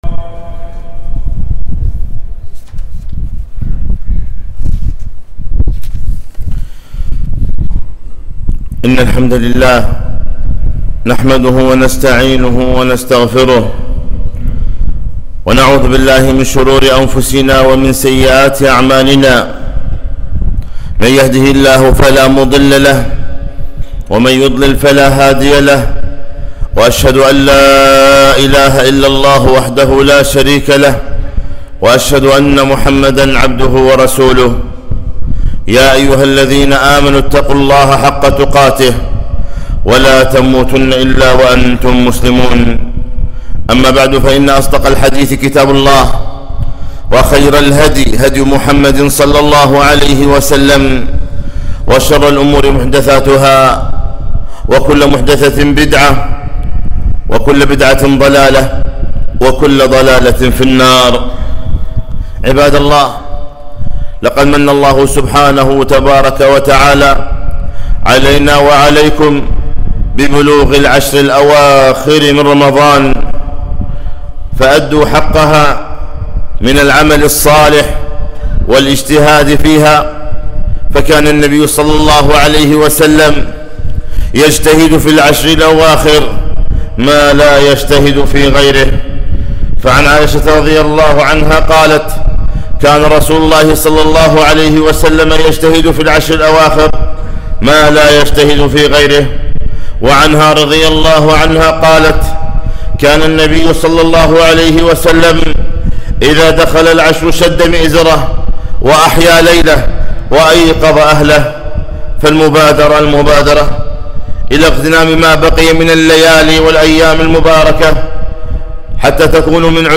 خطبة - وأقبلت العشر المباركة من رمضان